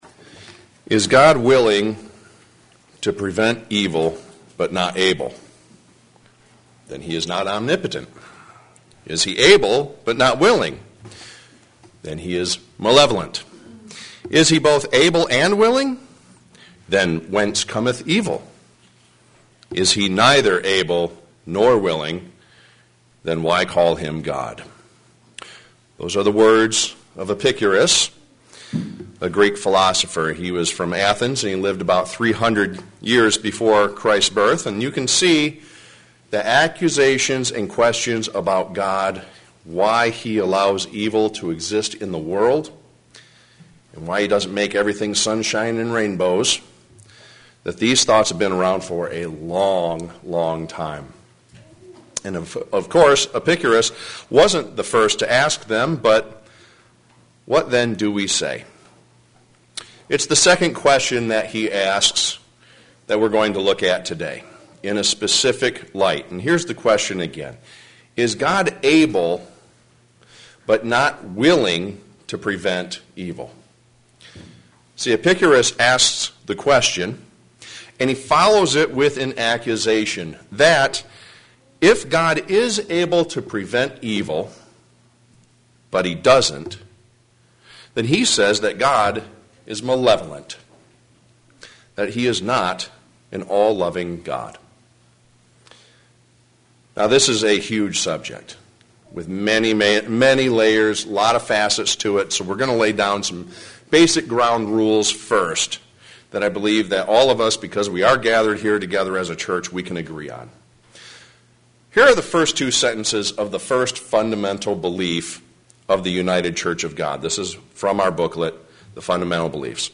Given in Flint, MI
Get answers to these questions here today. sermon Studying the bible?